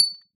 hollow stone break
Hollow.mp3